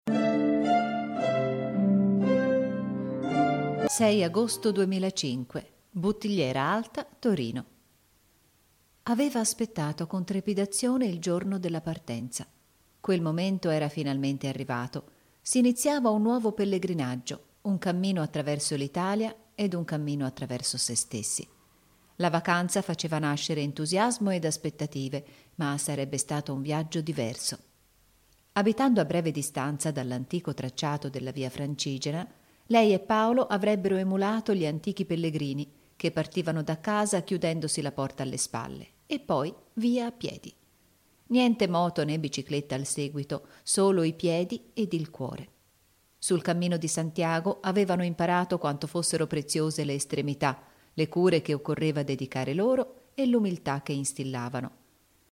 Sprecherin italienisch.
Kein Dialekt
Sprechprobe: Sonstiges (Muttersprache):
female italian voice over artist.